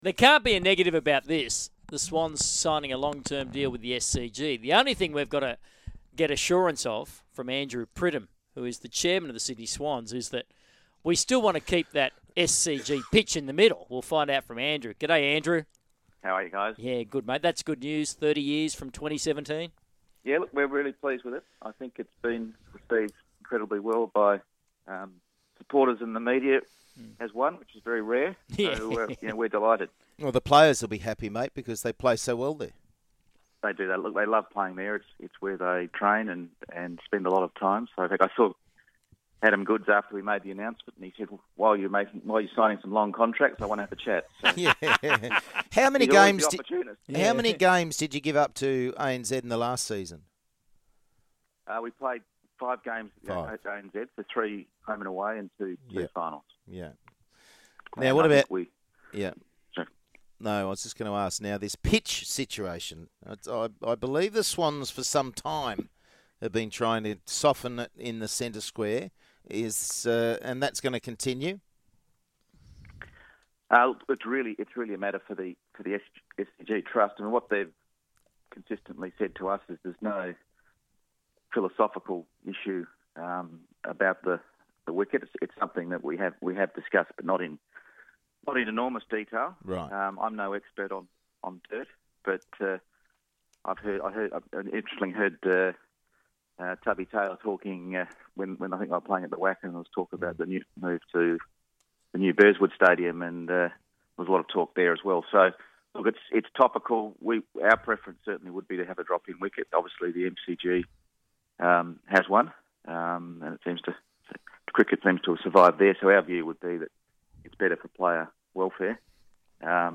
appeared on Sky Radio's Big Sports Breakfast on Tuesday November 25, 2014